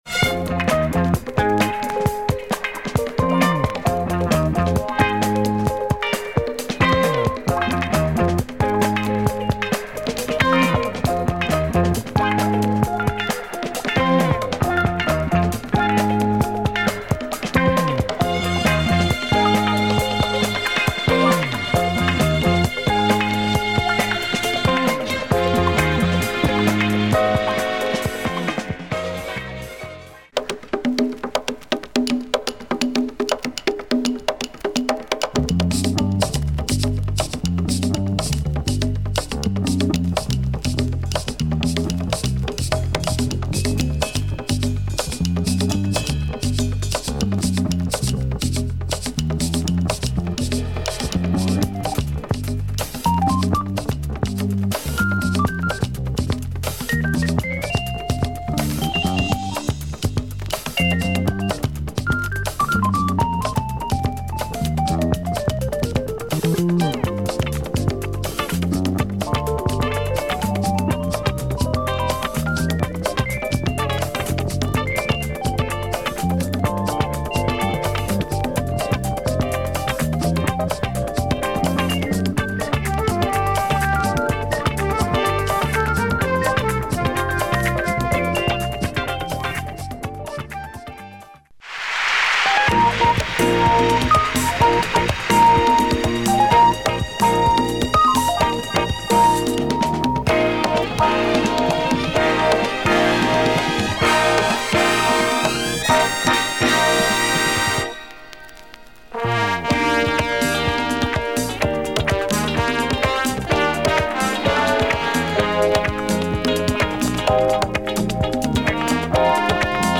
A superb jazz groove session from Peru !
Jazz